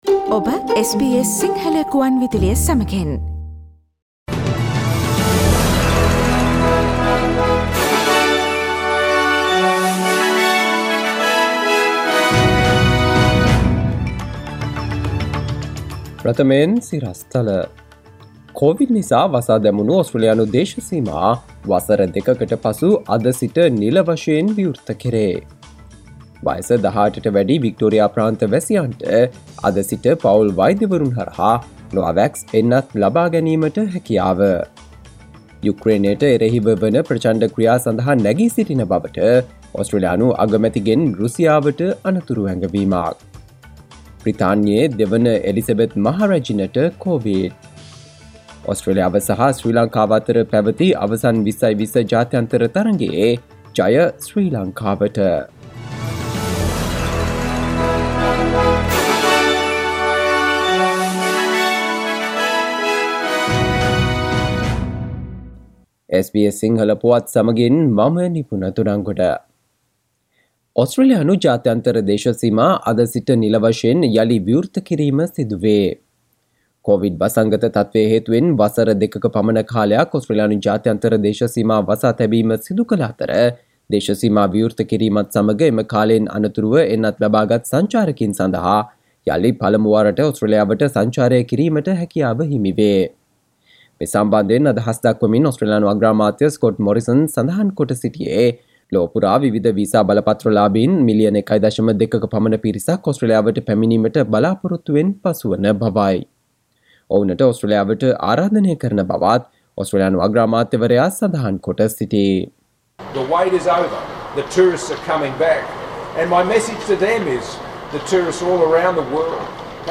සවන්දෙන්න 2022 පෙබරවාරි 21 වන සඳුදා SBS සිංහල ගුවන්විදුලියේ ප්‍රවෘත්ති ප්‍රකාශයට...